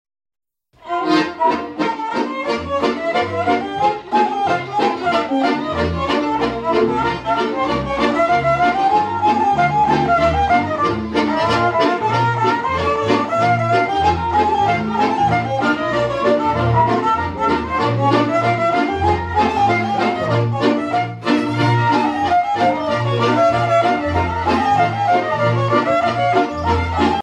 danse : kolo (Serbie)
Pièce musicale éditée